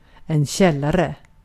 Uttal
Uttal Okänd accent: IPA: /ˈɕɛlˌarɛ/ IPA: /ˈɕɛlːarə/ Ordet hittades på dessa språk: svenska Översättning Substantiv 1. bodrum 2. mahzen Artikel: en .